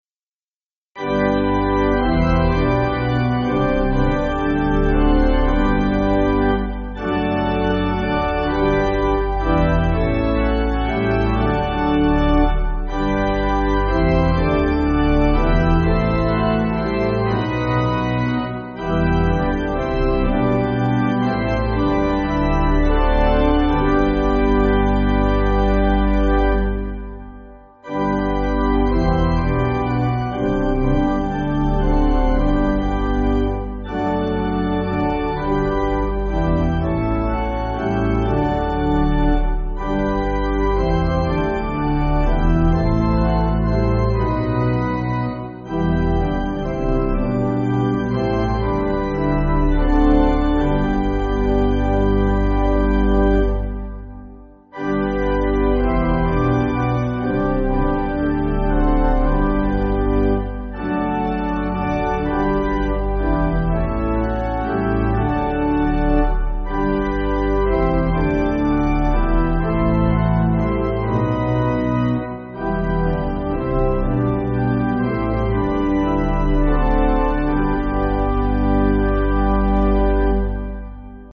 Organ
(CM)   4/G